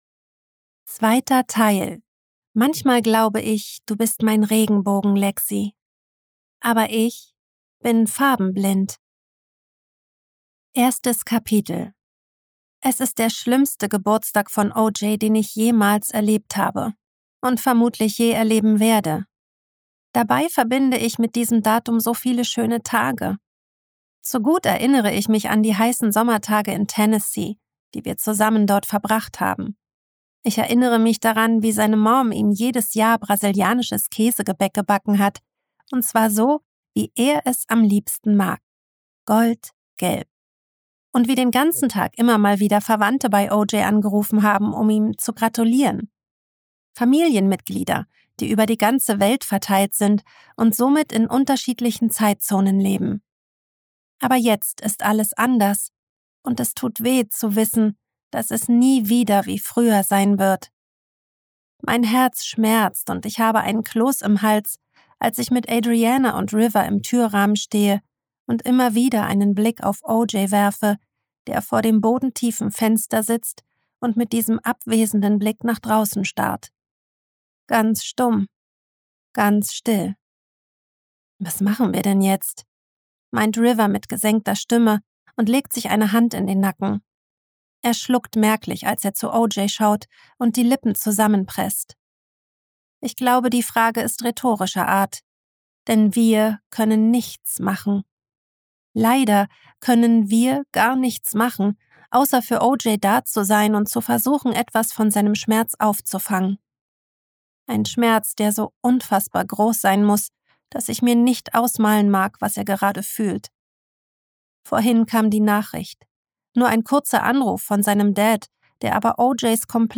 Willkommen in der Hörbuch Welt!